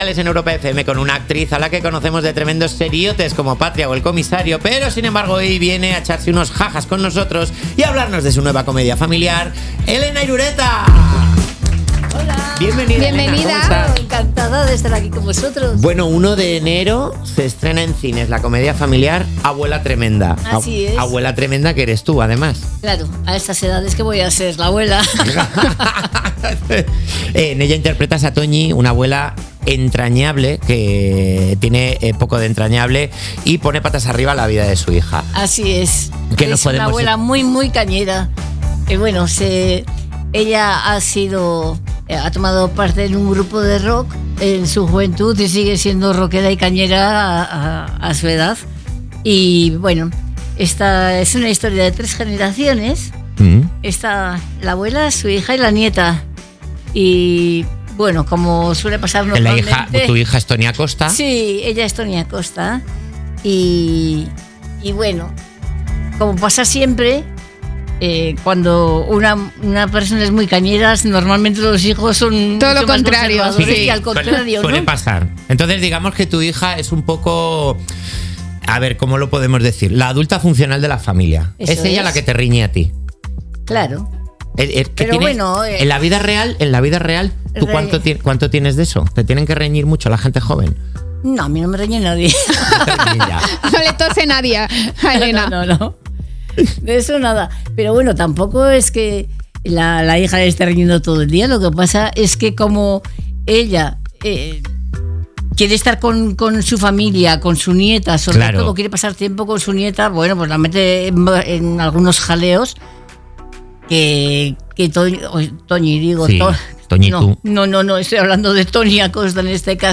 La entrevista de Elena Irureta en 'Cuerpos especiales'